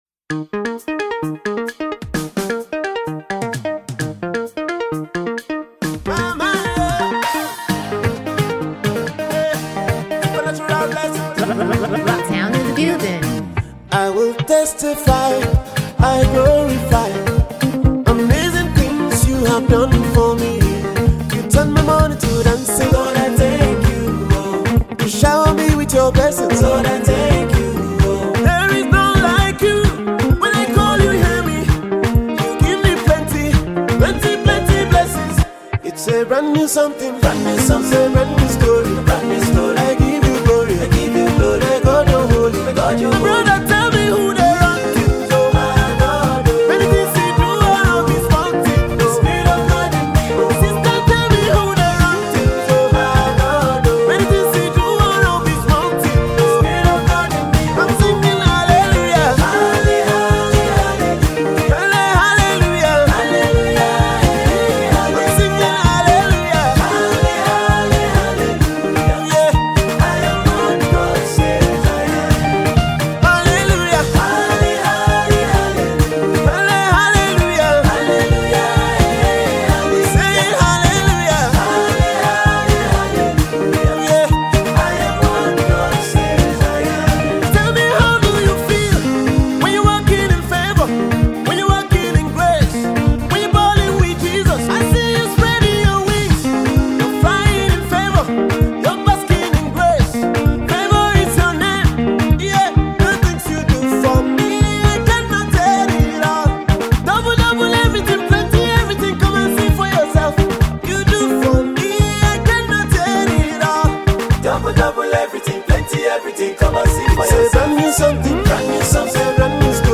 Acclaimed Nigerian gospel singer-songwriter
groovy tune
Afro-infused uptempo